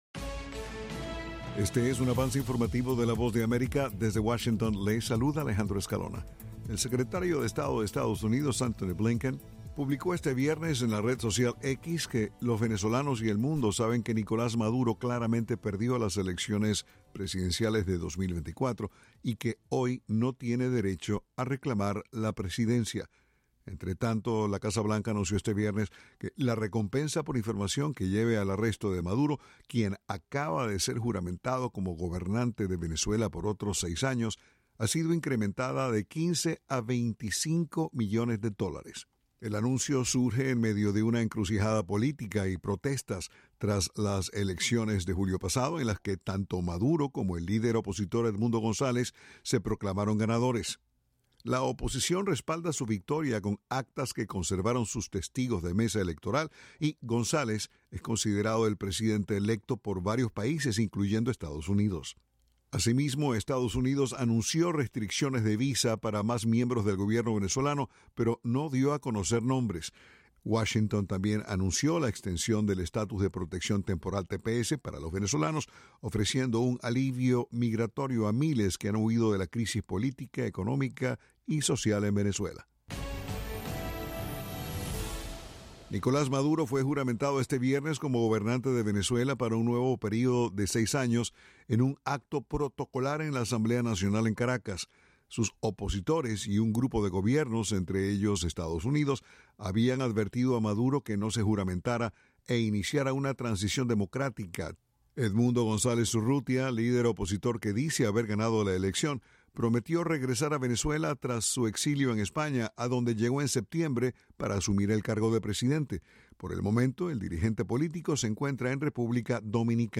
El siguiente es un avance informativo de la Voz de América. Informa desde Washington